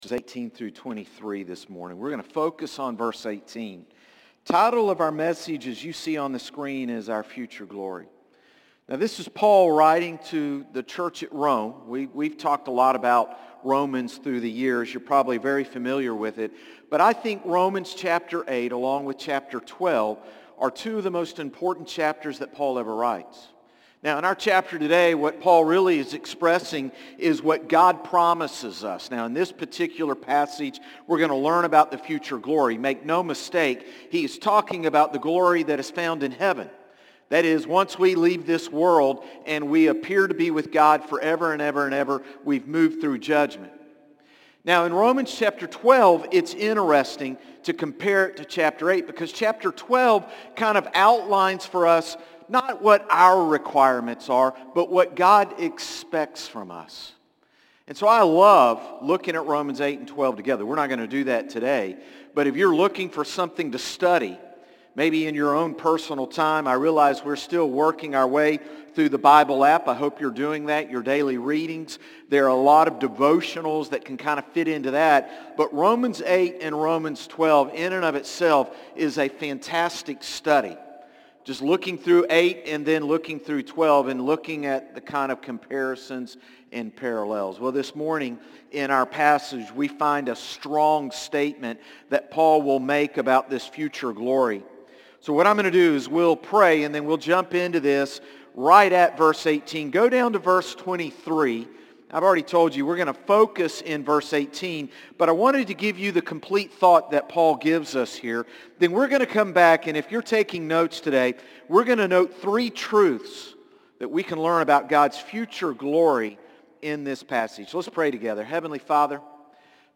Sermons - Concord Baptist Church
Morning-Service-9-22-24.mp3